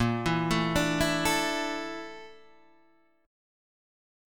A# Major 7th Flat 5th